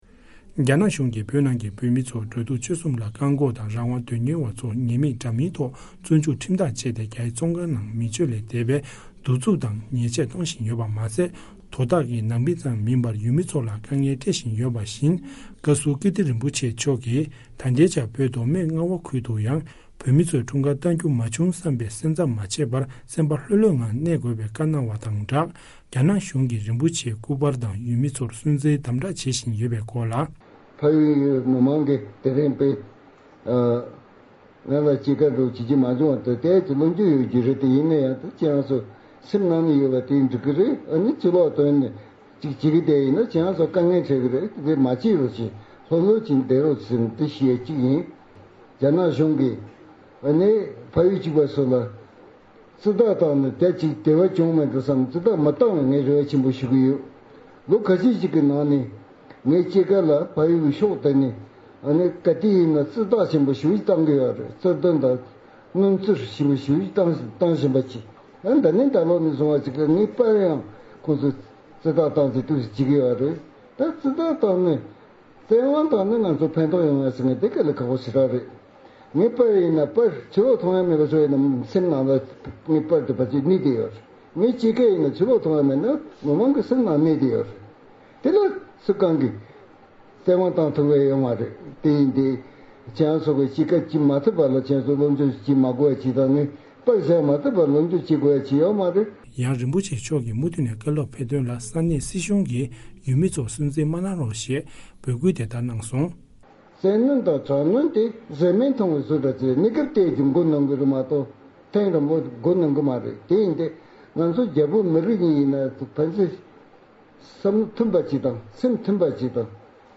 བཞུགས་སྒར་ནས་སྙན་སྒྲོན་གནང་གི་རེད།